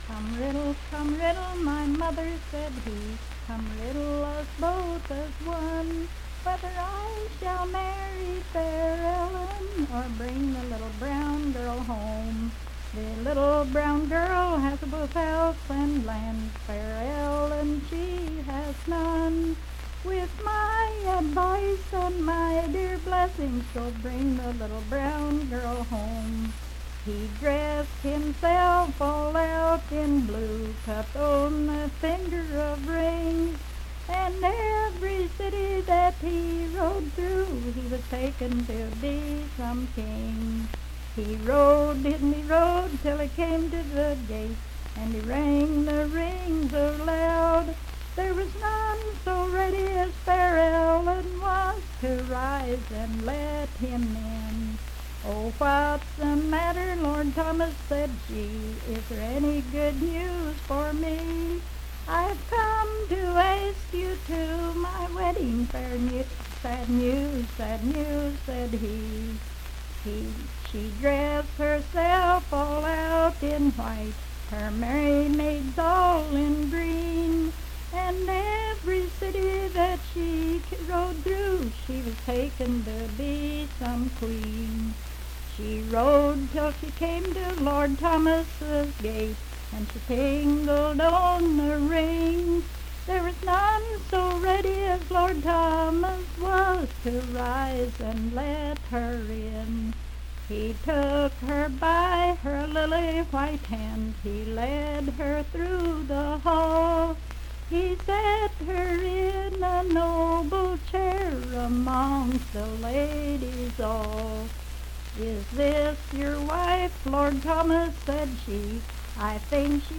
Unaccompanied vocal music
Verse-refrain. 15(4).
Voice (sung)
Hardy County (W. Va.), Moorefield (W. Va.)